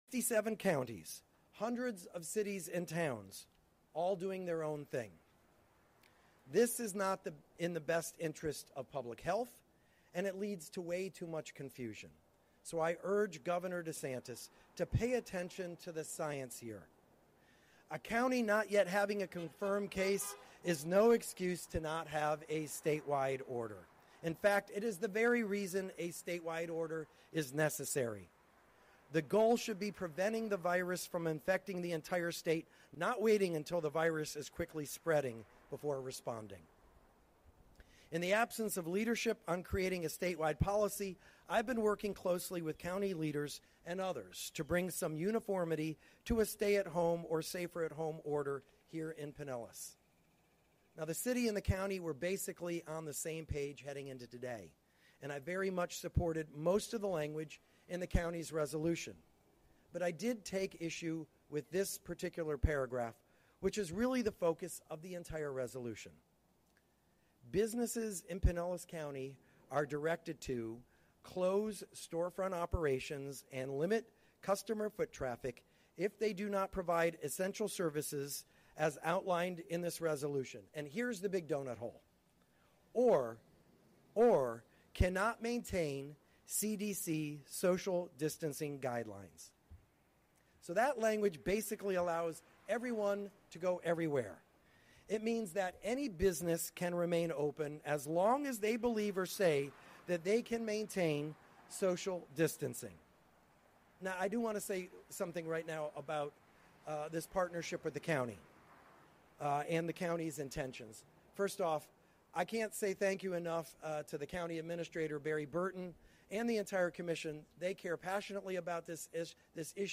Mayor Rick Kriseman Press Conference 3-25-20